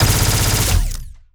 Added more sound effects.
GUNAuto_Plasmid Machinegun Burst_04_SFRMS_SCIWPNS.wav